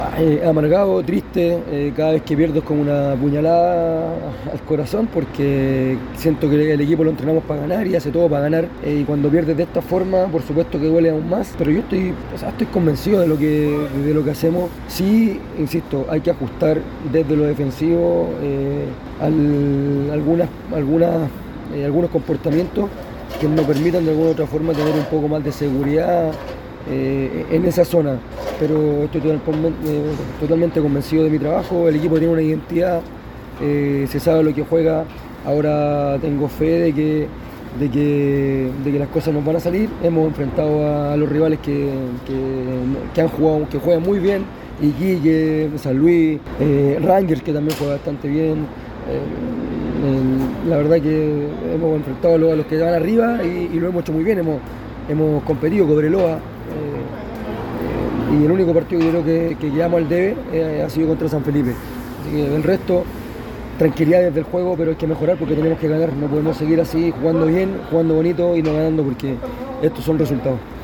Así lo reconoció en diálogo con Primera B Chile.